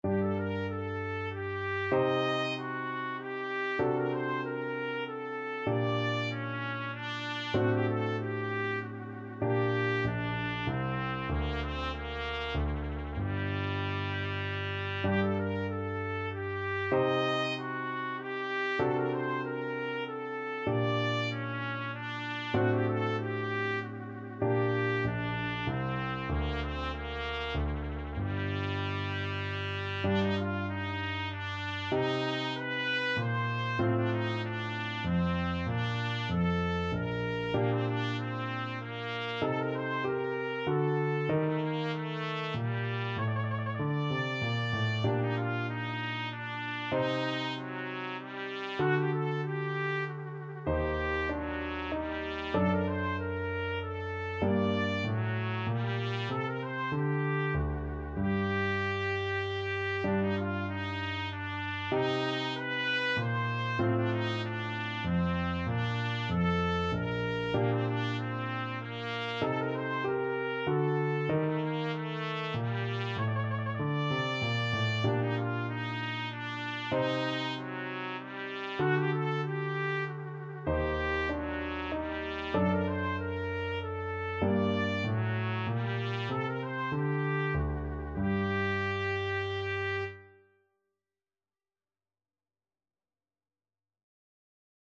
Classical Scarlatti, Domenico Sonata K.23 Trumpet version
Trumpet
3/8 (View more 3/8 Music)
F#4-Eb6
G minor (Sounding Pitch) A minor (Trumpet in Bb) (View more G minor Music for Trumpet )
Classical (View more Classical Trumpet Music)